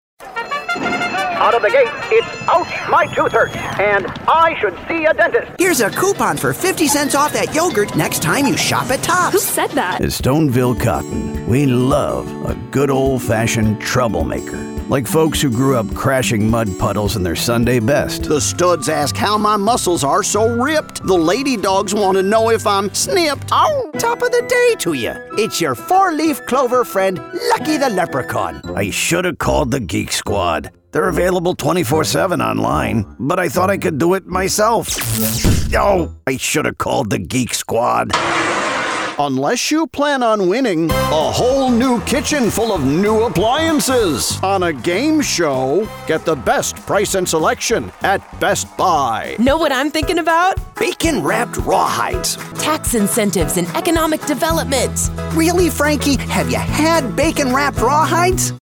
Voice
Gender: Male